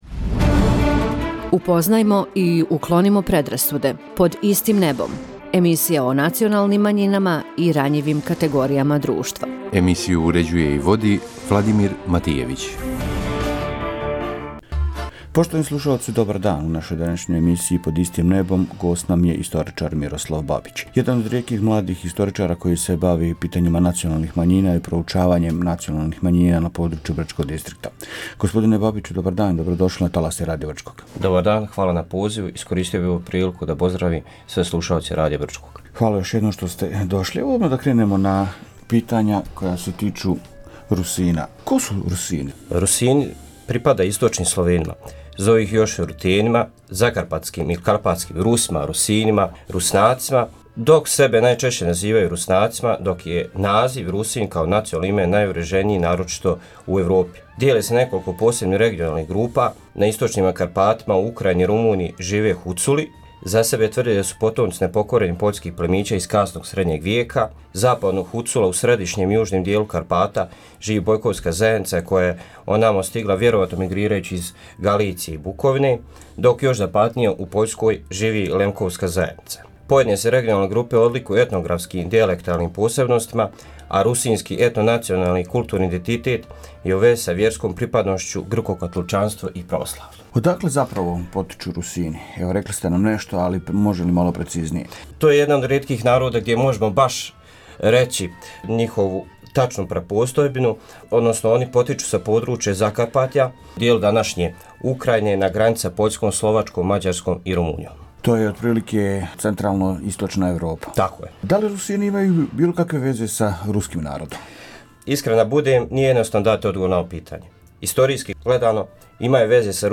Гост емисије “Под истим небом”